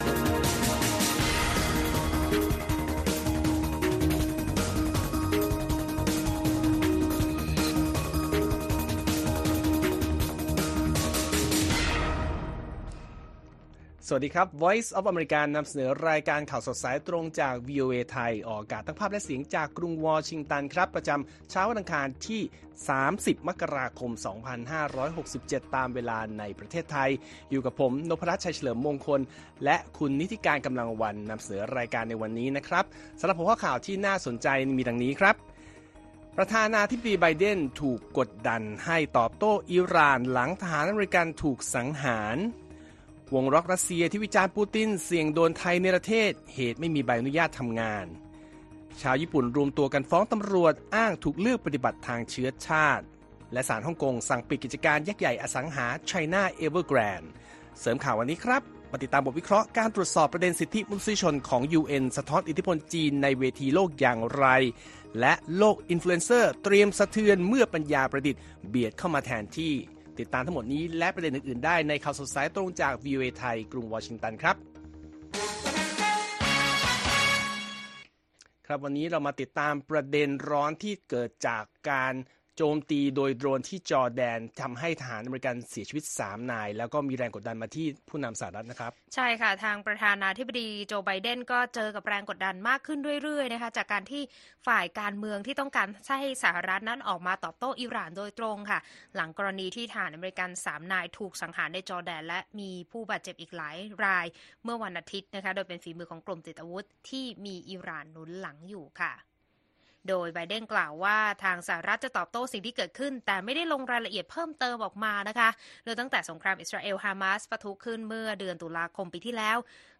ข่าวสดสายตรงจากวีโอเอไทย 6:30 – 7:00 น. วันอังคารที่ 30 มกราคม 2567